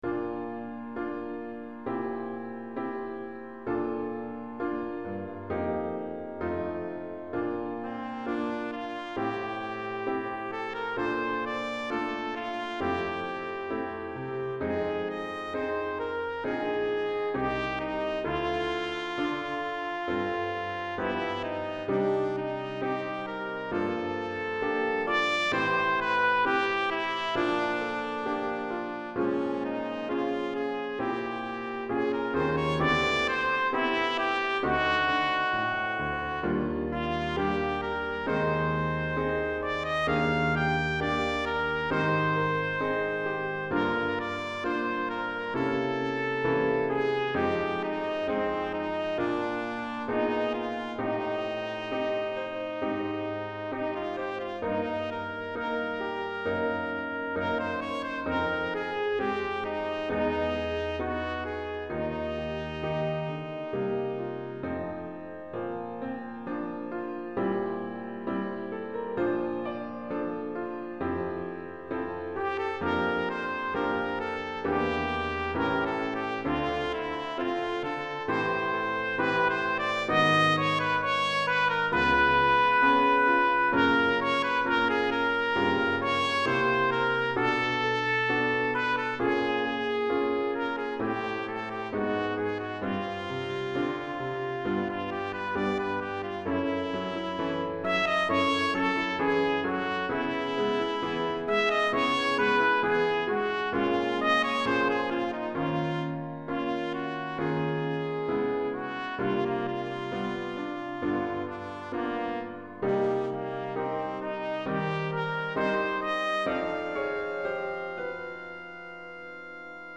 Répertoire pour Trompette ou cornet